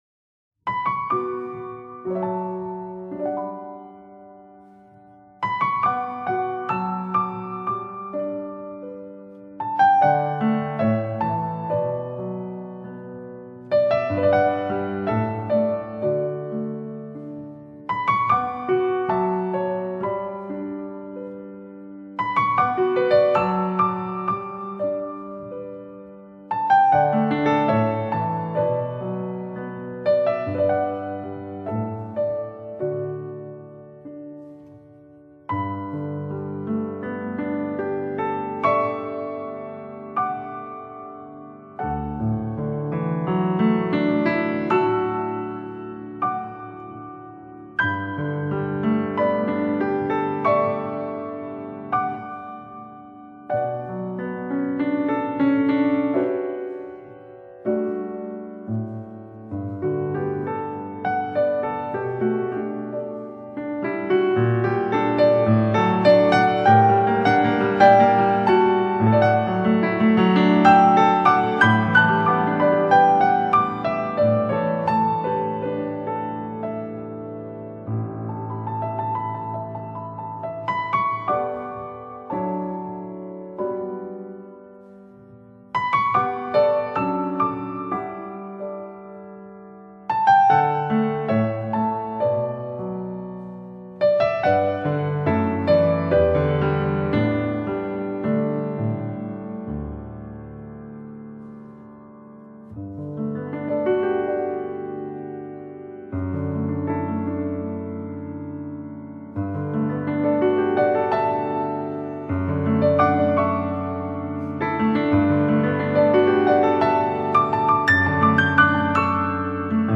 آهنگ بیکلام و آرامش بخش به نام «Memories of Those Days»